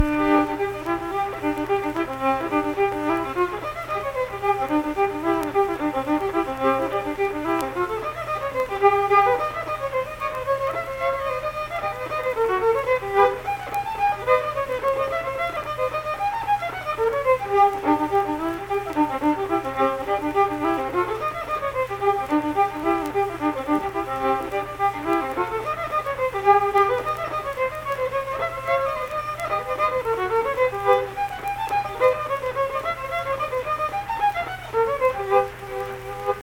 Unaccompanied vocal and fiddle music
Verse-refrain 2(2).
Instrumental Music
Fiddle
Saint Marys (W. Va.), Pleasants County (W. Va.)